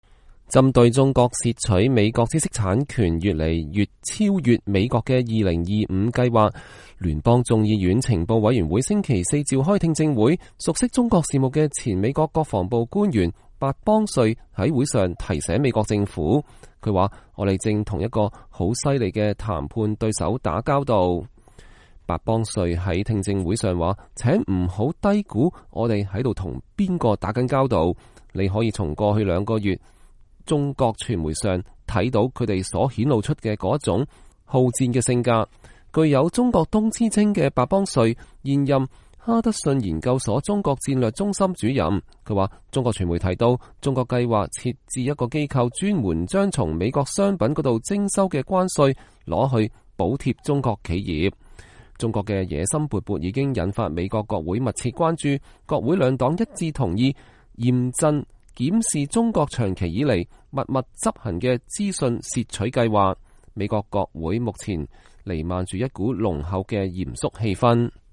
白邦瑞在國會聽證上會（7月19號）
“請別低估我們在和誰打交道，你可以從過去兩個月來中國媒體上看到他們所顯露出的那種好戰鬥性格，” 白邦瑞在聽證會上說。